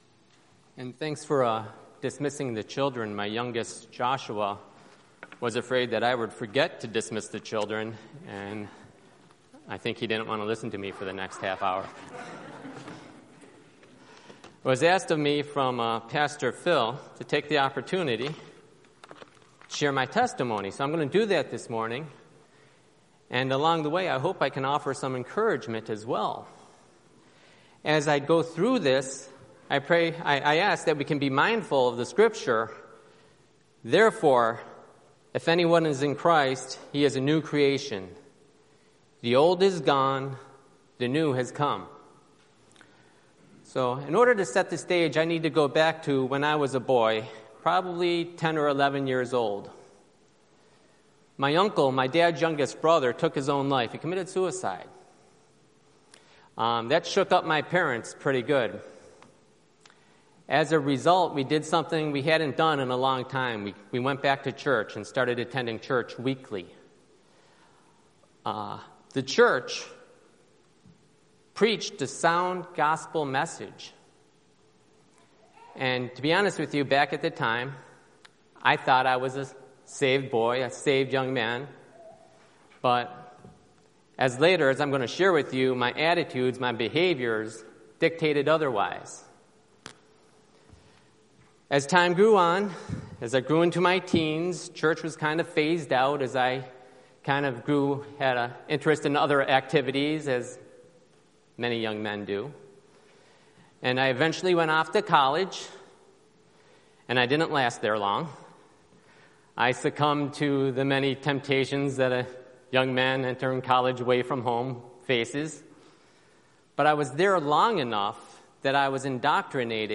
Galatians 2:15-21 Service Type: Sunday Morning Download Files Notes « Unity Testimony » Submit a Comment Cancel reply You must be logged in to post a comment.